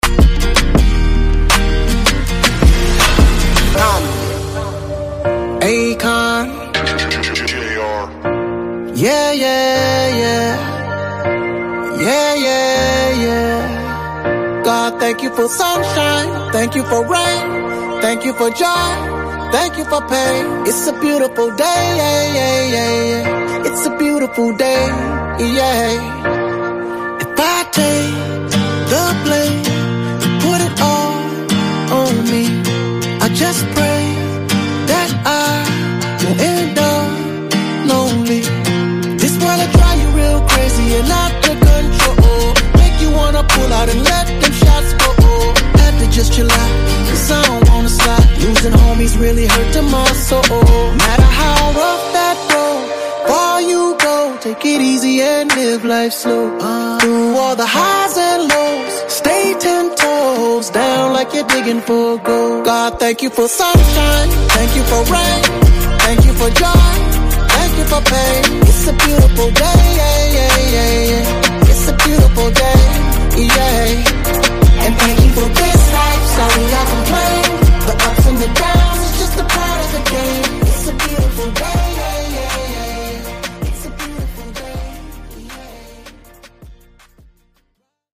Genres: R & B , RE-DRUM , TOP40
Clean BPM: 80 Time